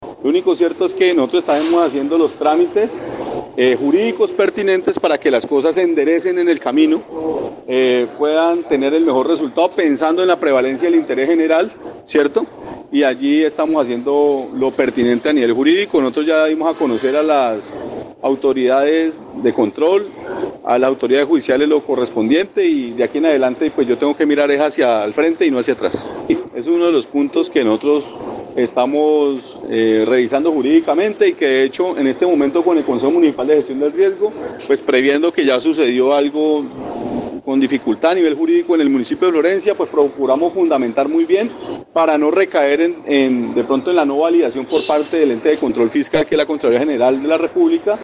ALCALDE_MONSALVE_ASCANIO_REPETICION_-_copia.mp3